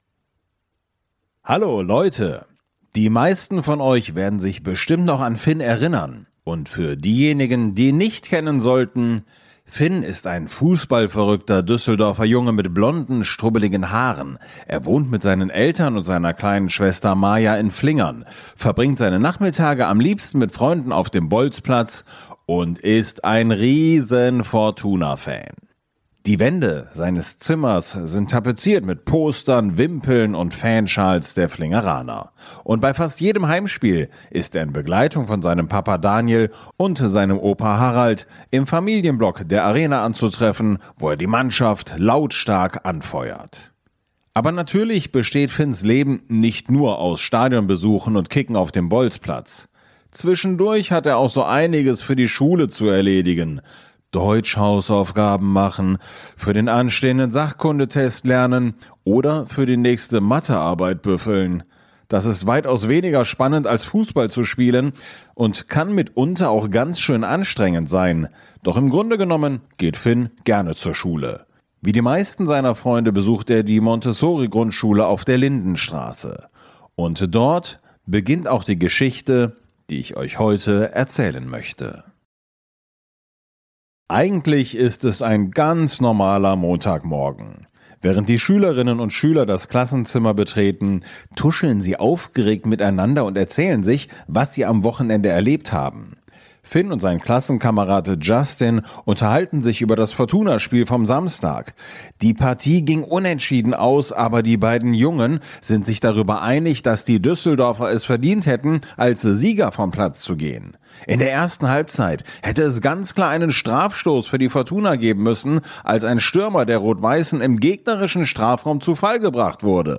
In der Hörspiel-Reihe „Finns Abenteuer" erlebt der junge Fußball-Fan Finn Geschichten rund um seinen Lieblingsverein – die Fortuna.